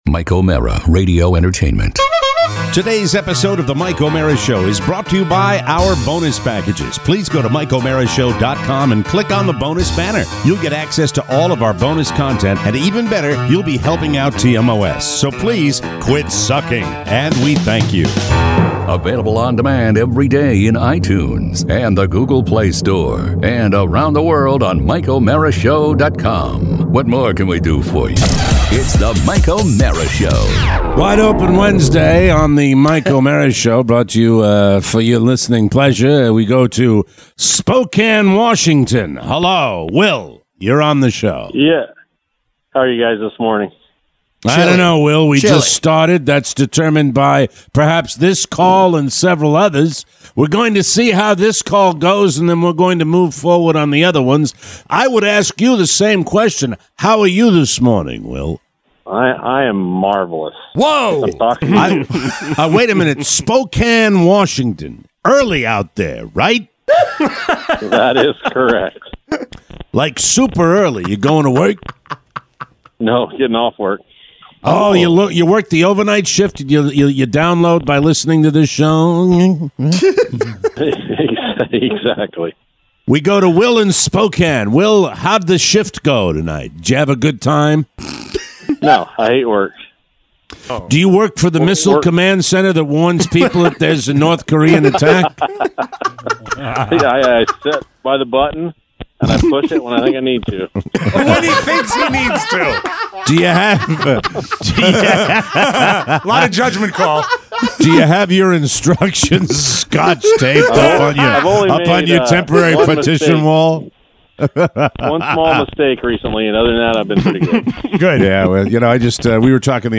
Your calls on the Helpline with an international twist!